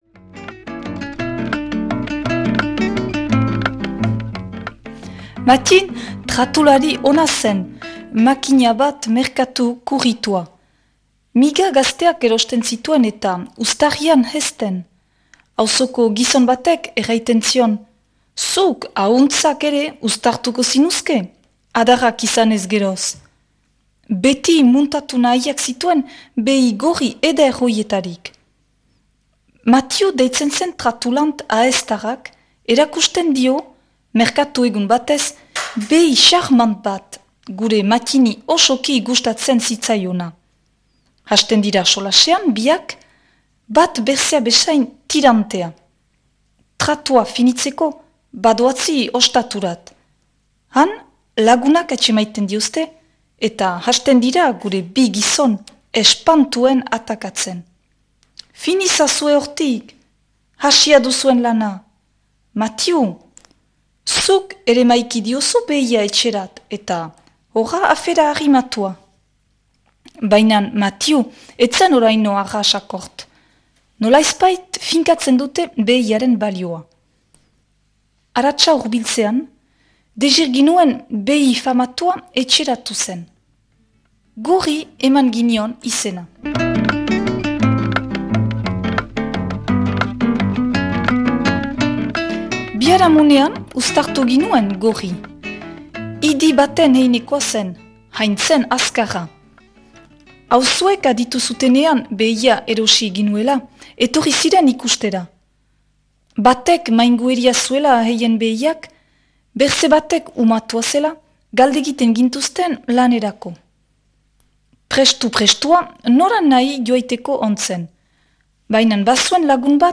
irakurketa